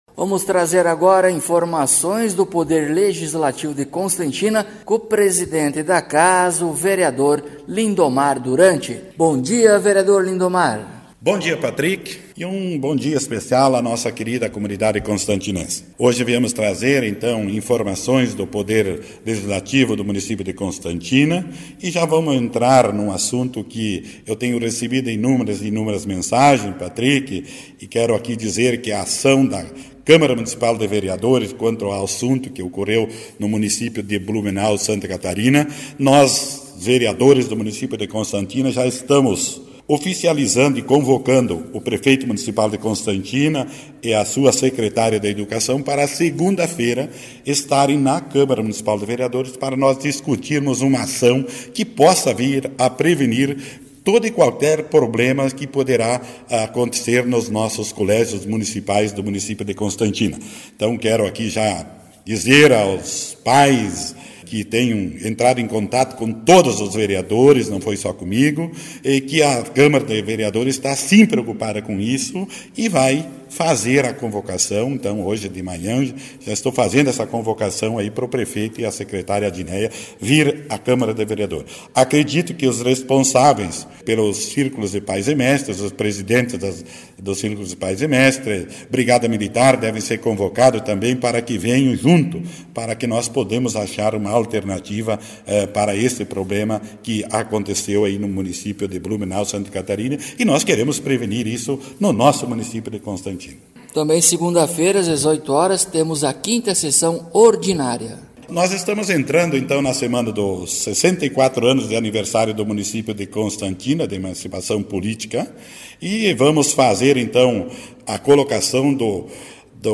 Após cumprimentos, o Vereador Lindomar Duranti inicia sua fala trazendo informações sobre uma convocação envolvendo o Prefeito Fidelvino Menegazzo e a Secretária de Educação Adineia Rosseto em razão do recente ataque a creche Cantinho Bom Pastor em Blumenau.